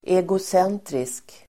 Ladda ner uttalet
Uttal: [egos'en:trisk]